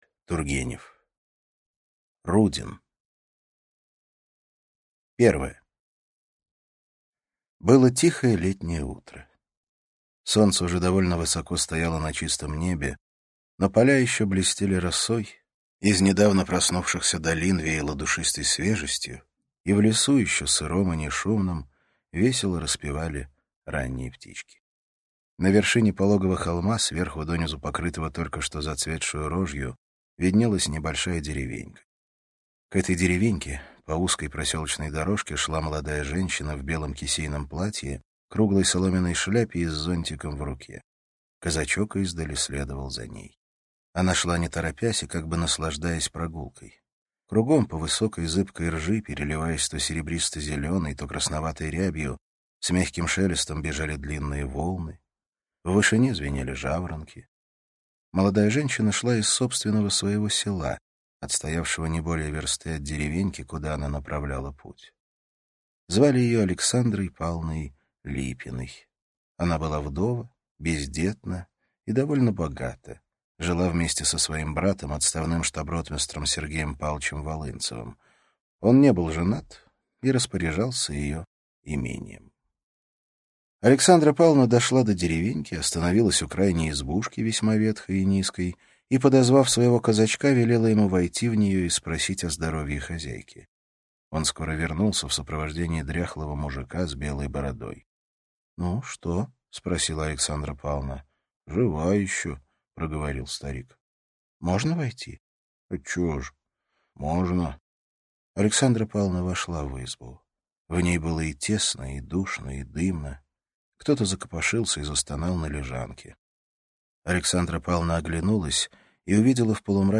Аудиокнига Рудин | Библиотека аудиокниг